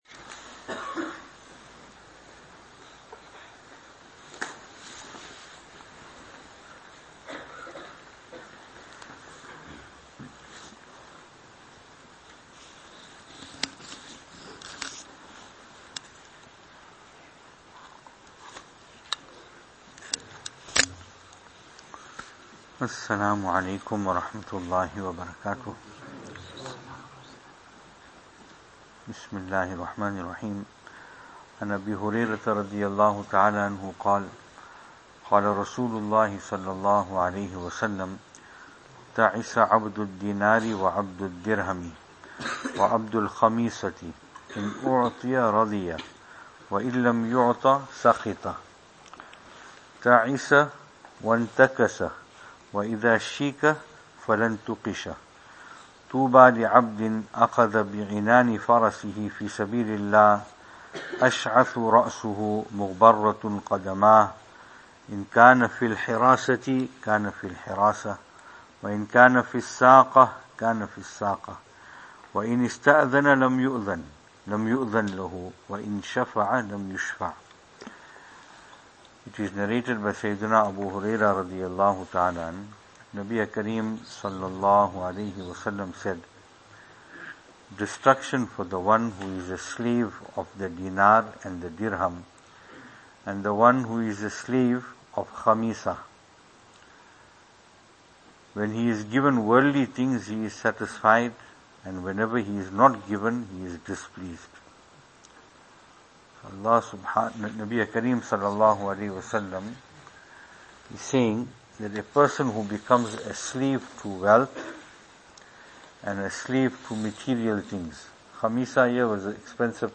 After Taraweeh Advices 8th Night